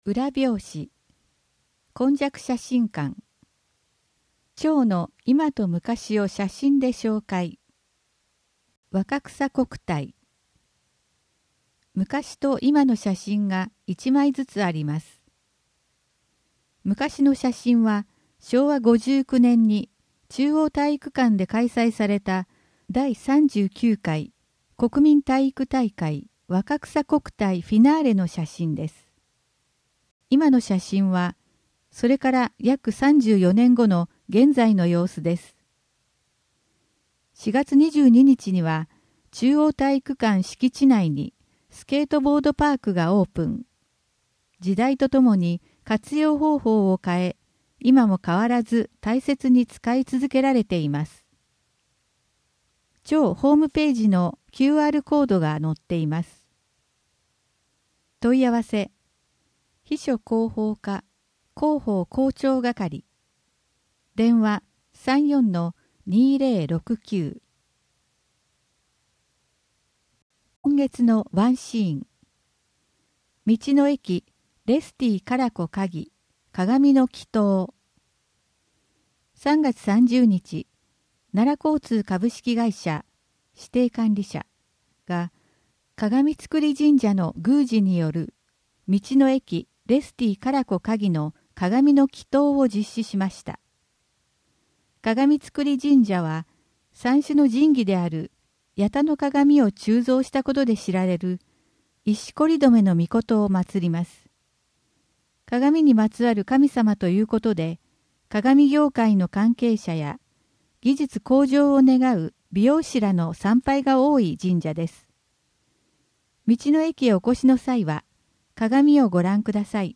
音訳広報たわらもと